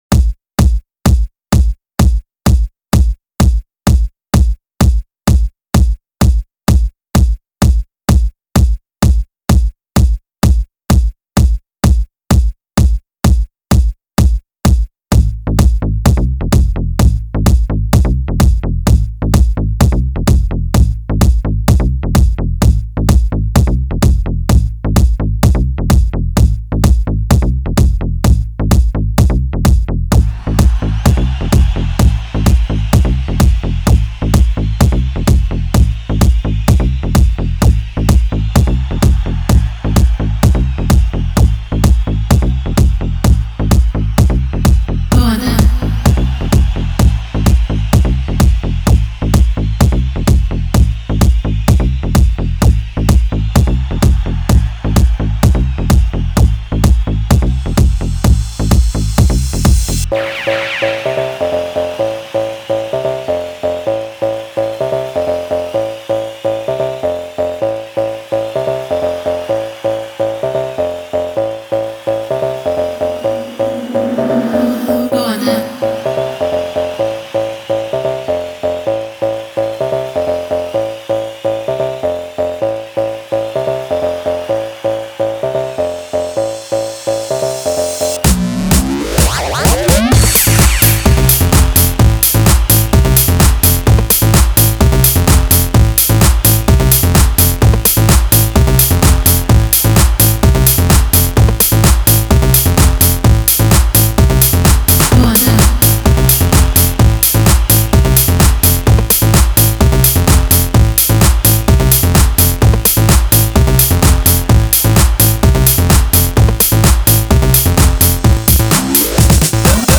Жанр:Dance